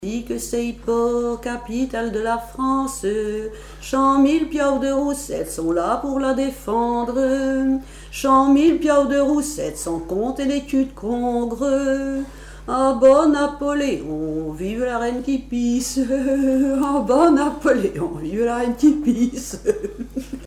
Chansons et commentaires
Pièce musicale inédite